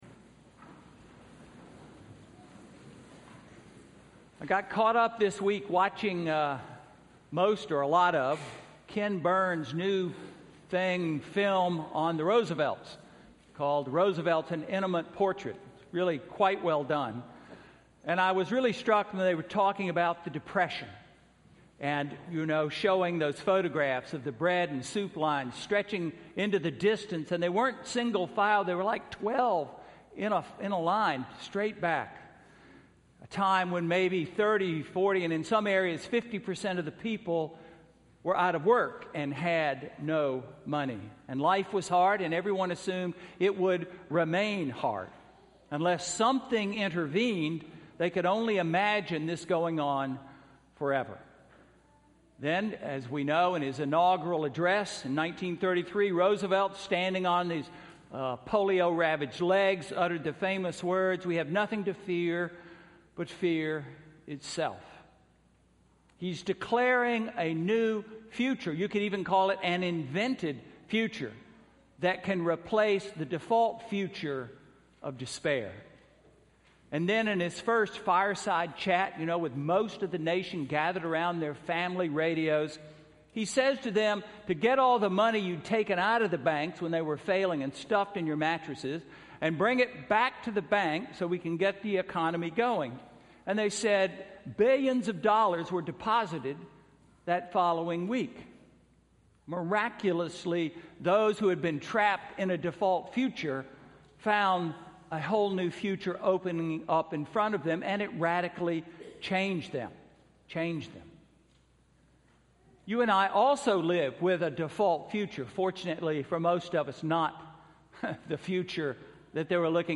Sermon–September 21, 2014